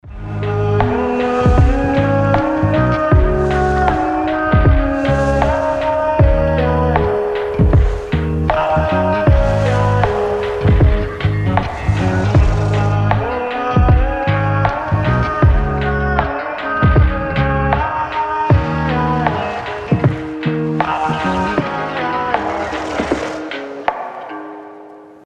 Хип-хоп
спокойные
биты